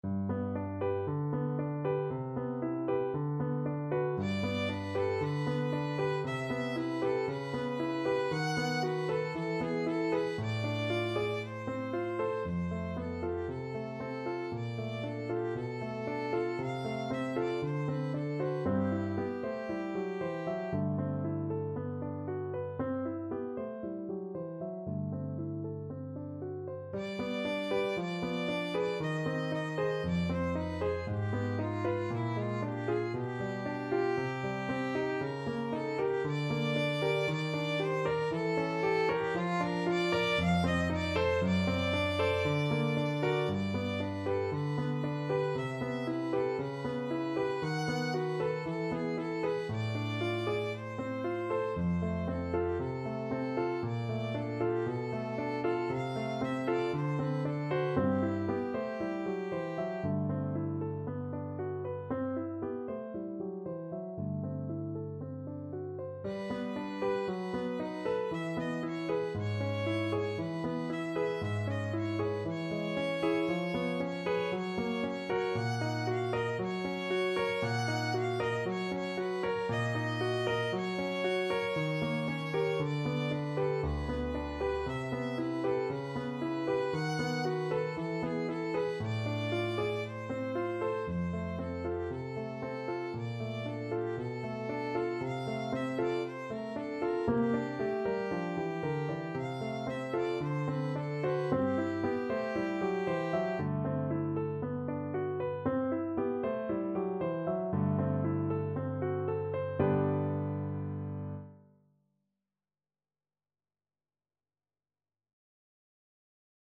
Chanson d'amour Violin version
His music is characterized by unusual harmonies and modulations
4/4 (View more 4/4 Music)
G major (Sounding Pitch) (View more G major Music for Violin )
Allegro moderato =116 (View more music marked Allegro)
Classical (View more Classical Violin Music)